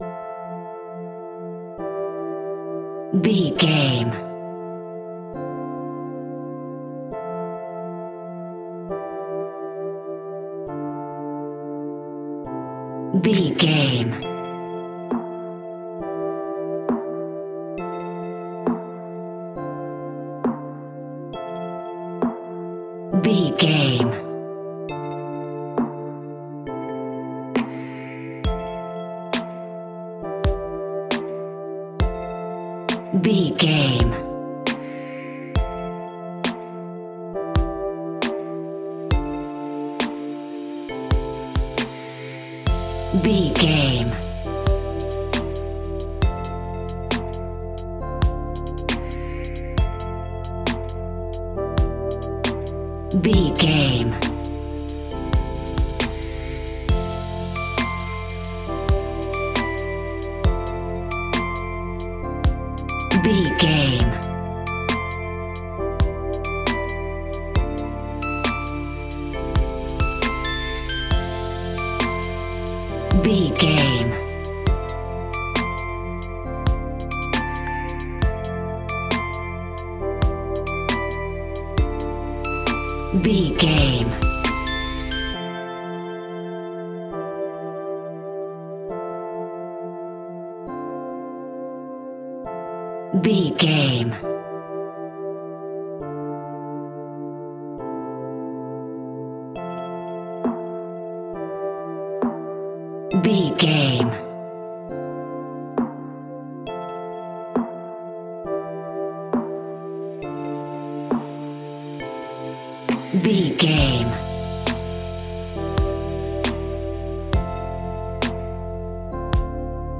Aeolian/Minor
hip hop
laid back
groove
hip hop drums
hip hop synths
piano
hip hop pads